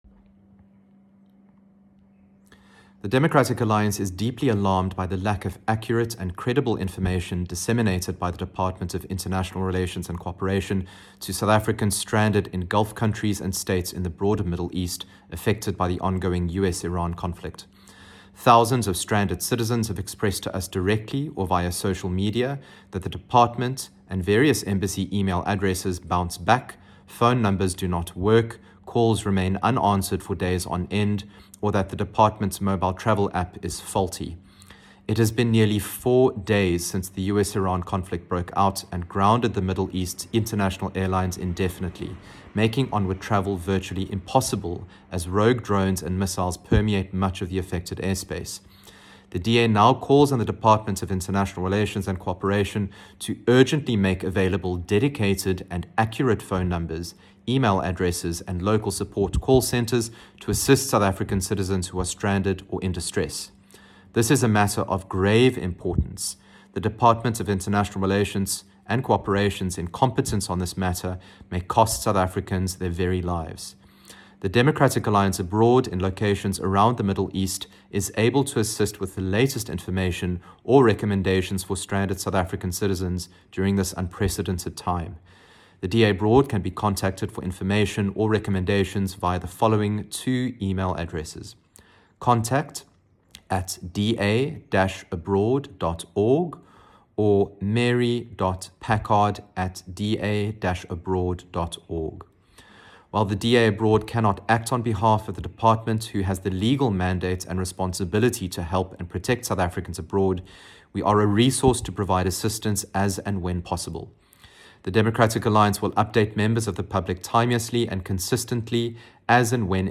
soundbite by Ryan Smith MP.